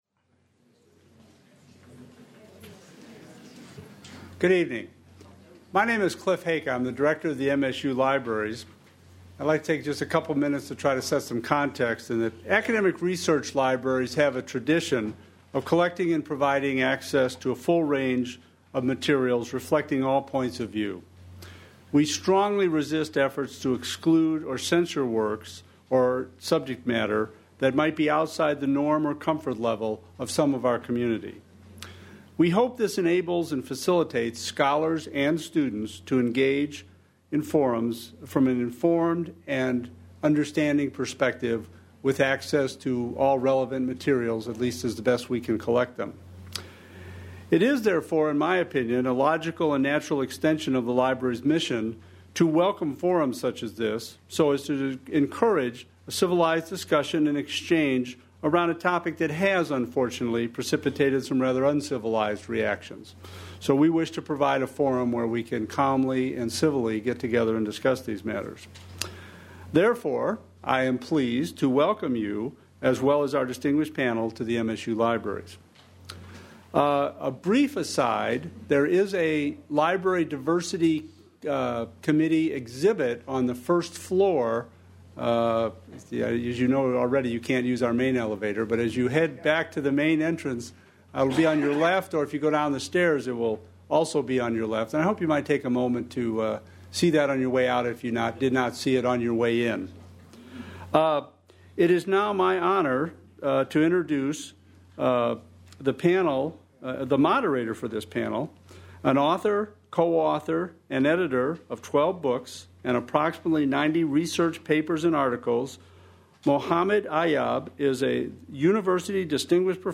The event is sponsored by the MSU Libraries and the MSU Muslim Studies Program and is conducted in response to the burning of a Qur'an in East Lansing on September 11, 2010.
Muslim Studies Program Subjects Christianity Christianity and other religions Interfaith relations Islam Material Type Sound recordings Series Library colloquia series (Michigan State University. Libraries) Language English Extent 01:59:15 Venue Note Recorded at Michigan State University Main Library, Oct. 27, 2010, by the Vincent Voice Library.